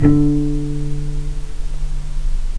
healing-soundscapes/Sound Banks/HSS_OP_Pack/Strings/cello/pizz/vc_pz-D3-pp.AIF at bf8b0d83acd083cad68aa8590bc4568aa0baec05
vc_pz-D3-pp.AIF